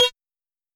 S 78_Cow.wav